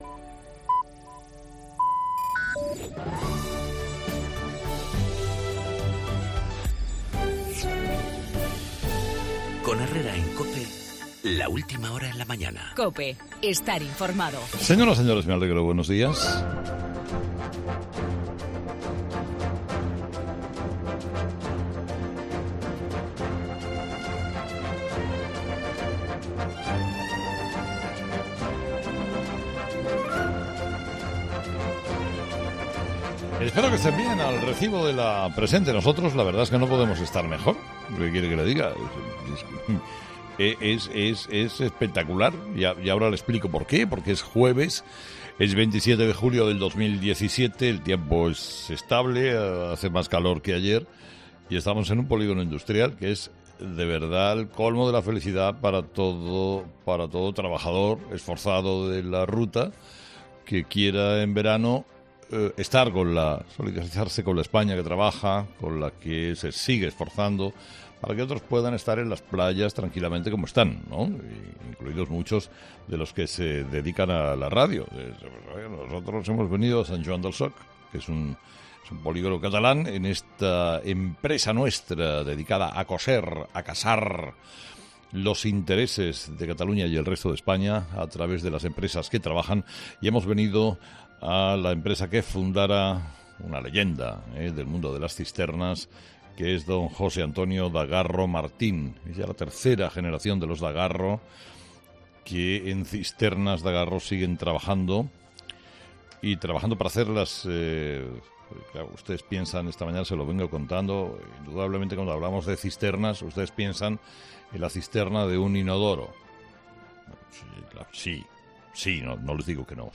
Monólogo de las 8 de Herrera 'Herrera a las 8'